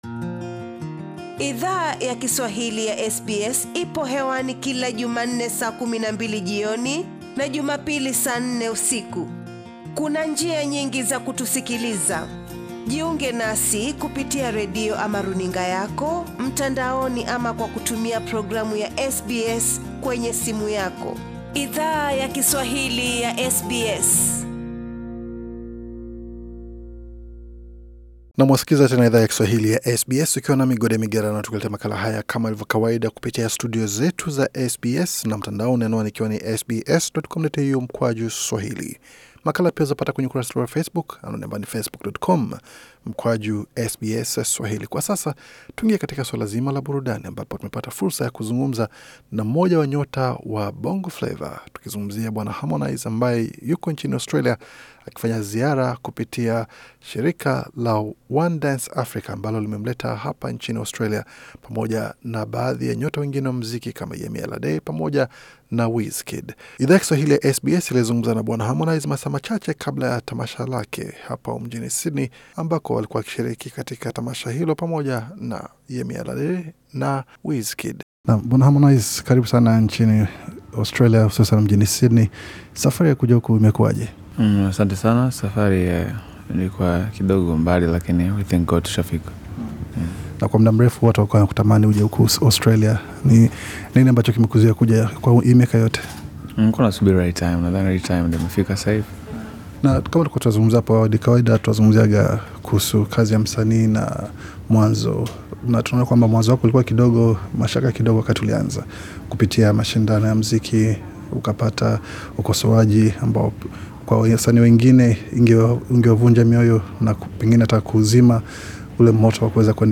Katika mazungumzo haya, Harmonize alieleza Idhaa ya Kiswahili ya SBS kuhusu tamasha ya 1Dance Africa, pamoja na vinavyo mshawishi kutunga mashairi ya miziki yake.